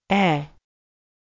母音/e/(/ɛ/)は、日本語の「エ」と同じような音と表現されることも多いですが、厳密には異なる音になります。(日本語よりも少し音程を低くするイメージ)
母音/e/(/ɛ/)のみの発音
・短母音
・弛緩母音 (しかんぼいん/lax vowel)
母音ɛのみの発音.mp3